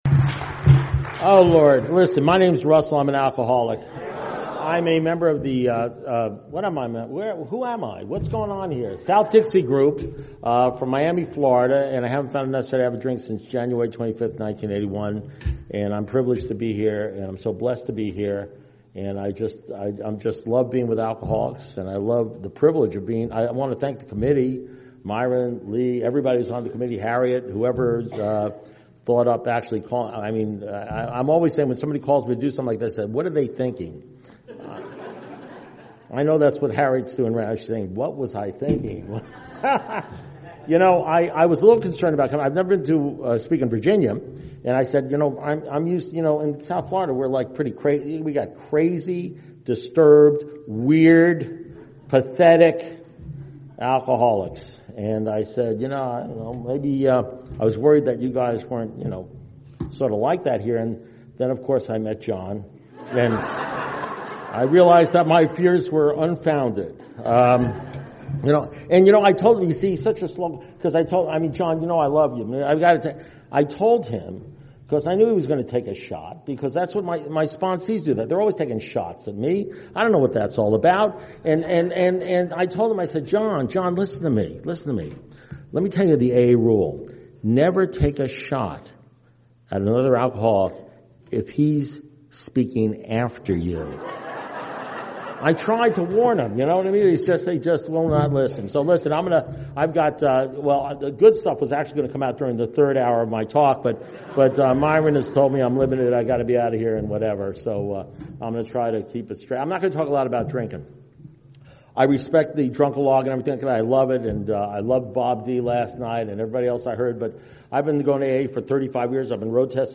2016 Virginia Area Conference https
AA Speaker Recordings AA Step Series Recordings Book Study